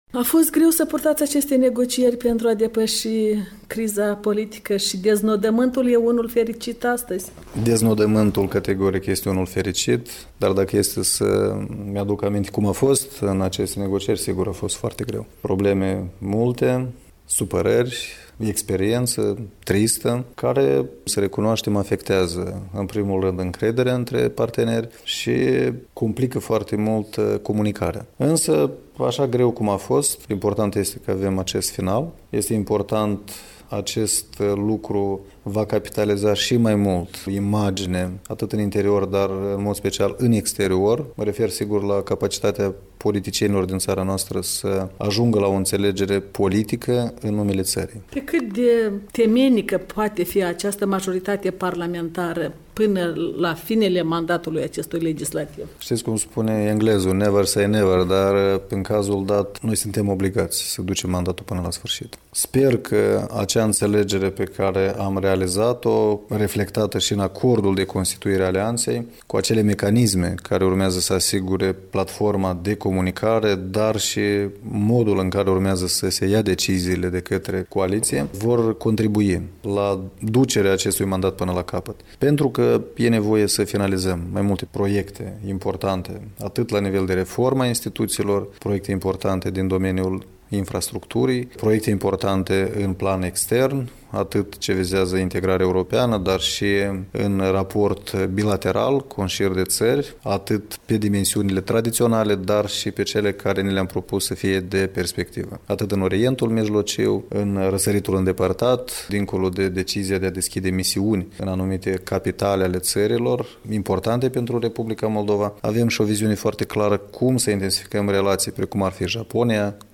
Un interviu cu Vlad Filat, președintele PLDM